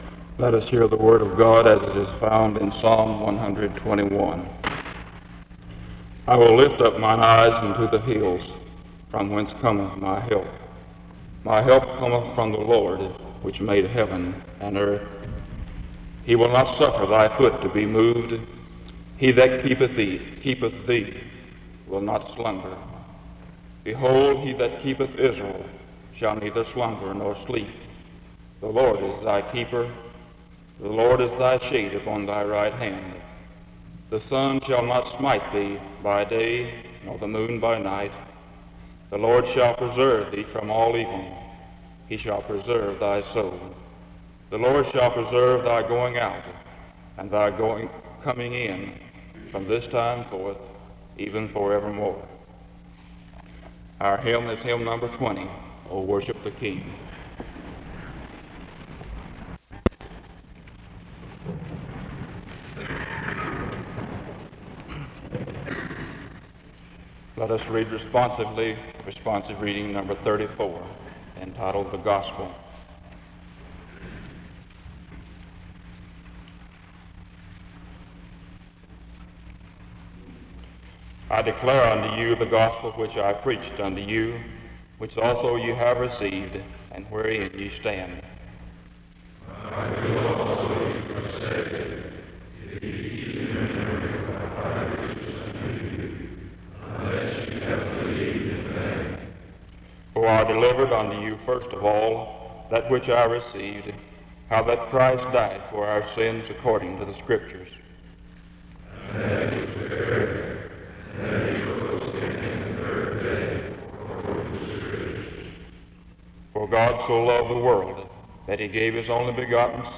The service starts with an opening scripture reading from 0:00-0:53. A responsive reading occurs from 1:07-3:10. A prayer is offered from 3:11-5:08.